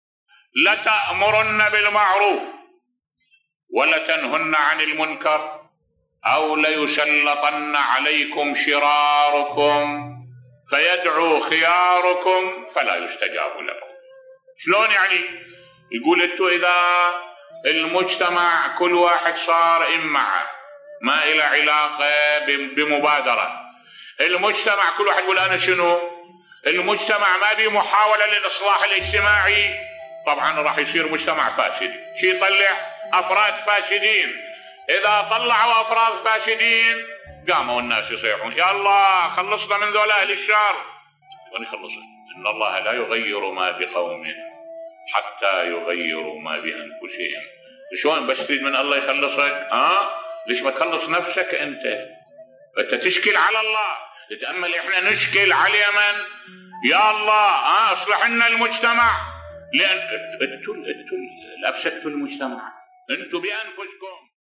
ملف صوتی ان الله لا يغير ما بقومٍ حتى يغيروا ما بأنفسهم الواقع المؤلم بصوت الشيخ الدكتور أحمد الوائلي